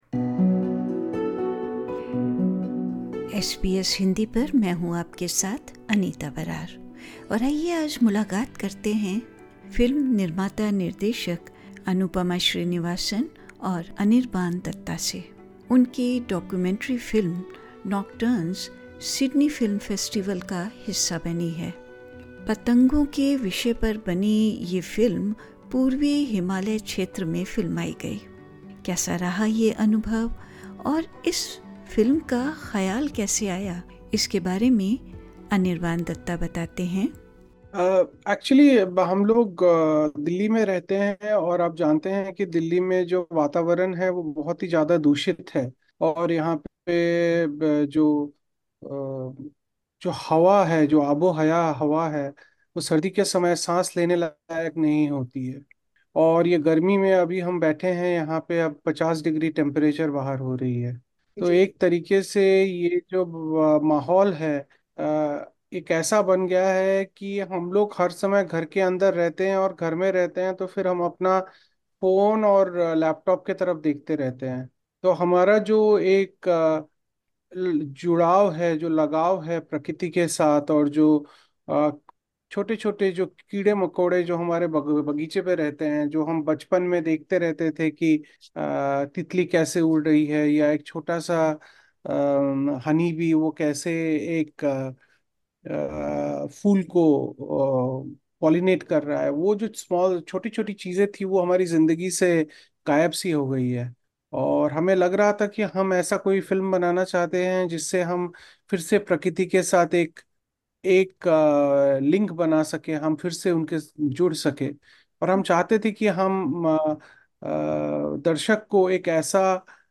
In an interview with SBS Hindi